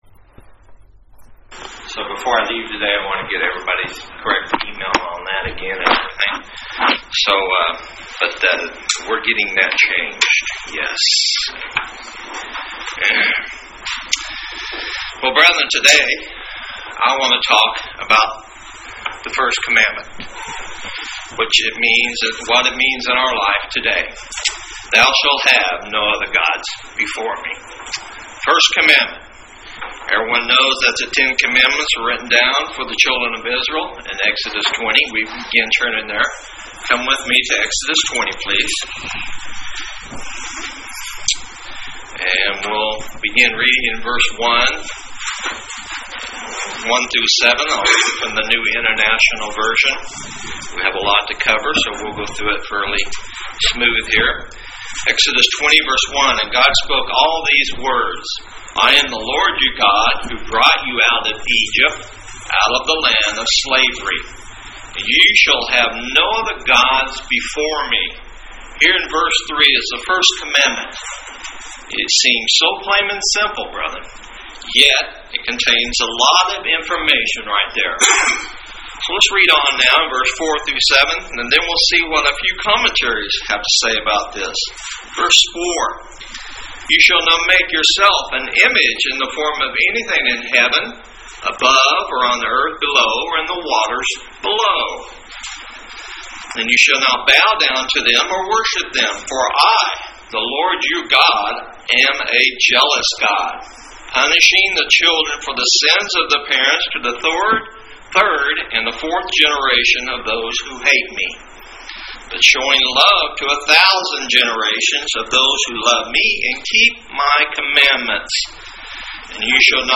Very timely sermon for Gods people today.